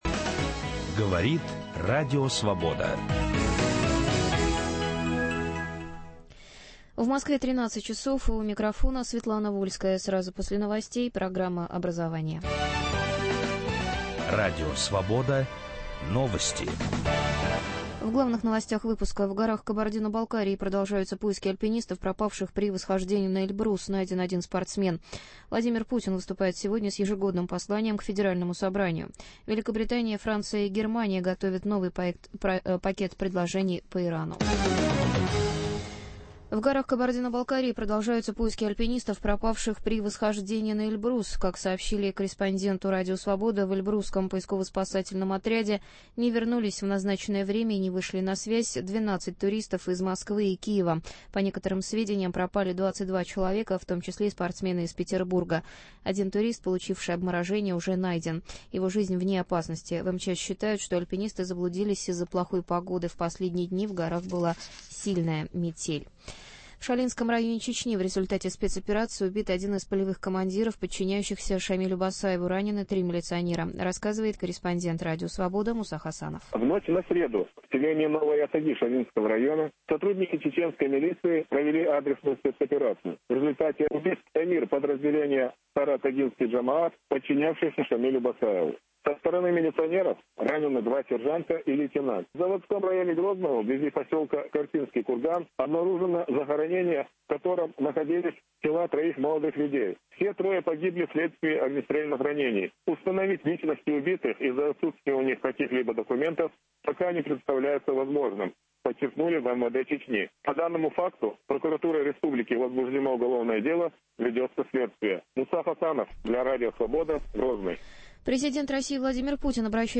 Проблема скинхедов в России. Какая воспитательная работа нужна, чтобы устранить это явление. В студии радио "Свобода"